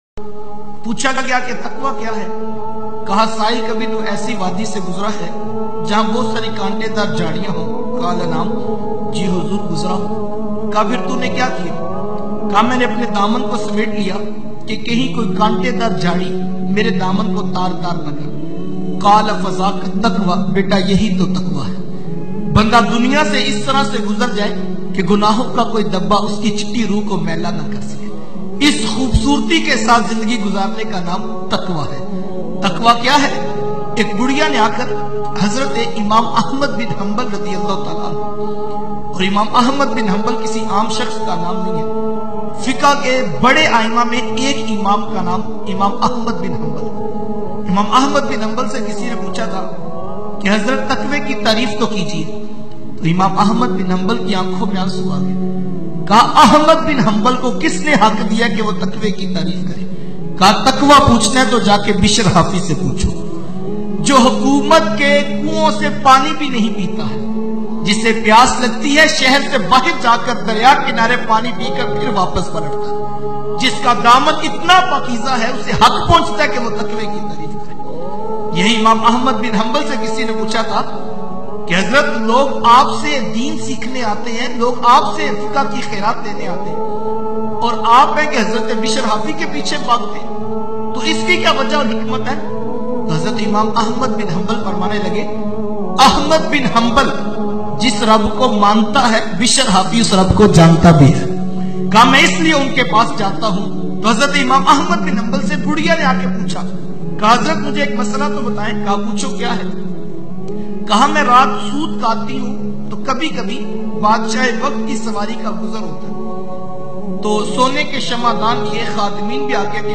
IMAM AHMAD BIN HUMBAL OR TAKWA bayan mp3